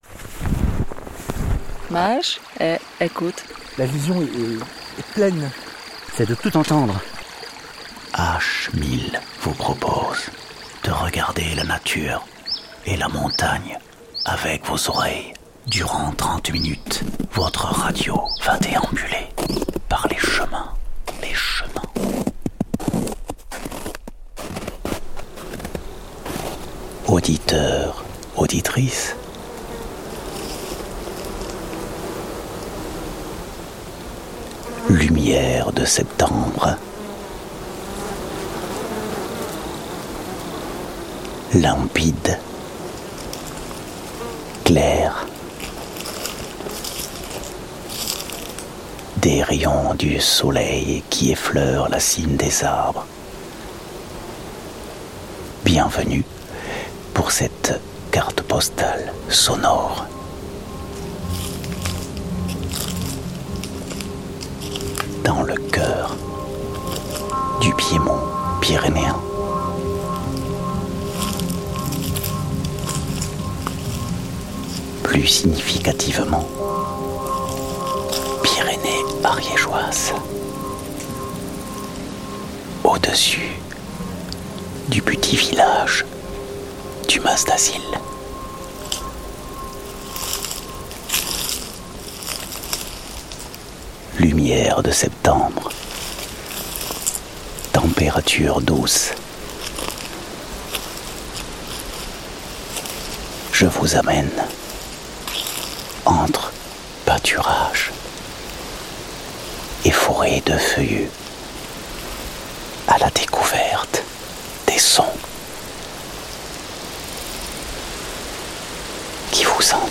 Cette semaine dans H1000, découvrez une carte postale auditive autour du Mas-d'Azil !